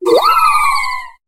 Cri de Phyllali dans Pokémon HOME.